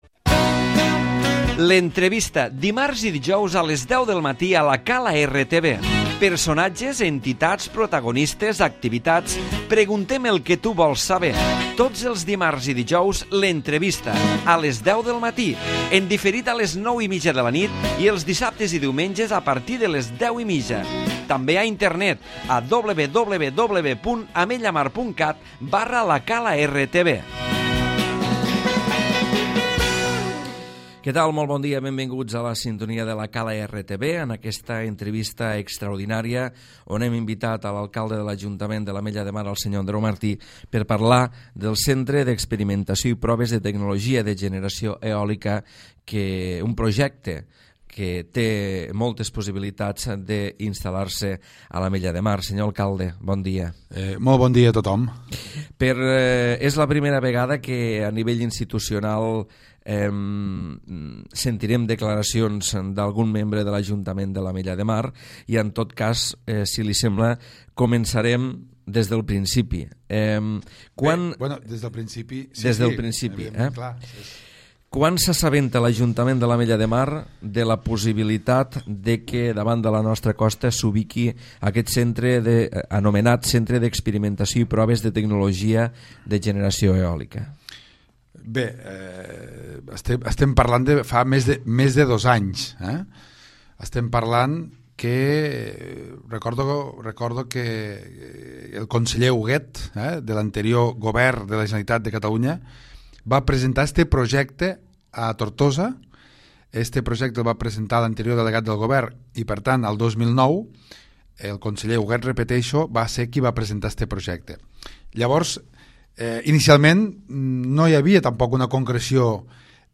L'Entrevista
Avui, a l'Entrevista, l'alcalde Andreu Martí ens ha parlat sobre el parc eòlic marí.